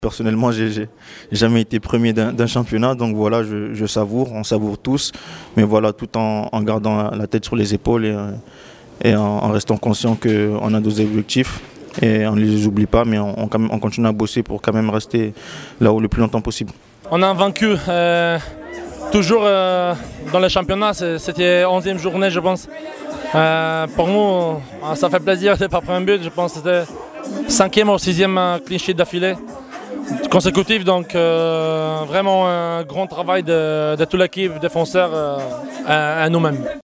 Jérémie Boga l'attaquant du Gym, et Marcin Bulka le gardien étaient très fiers, après la rencontre.
On continue à bosser pour quand même rester là le plus longtemps possible " confie Jérémie Boga au micro du club.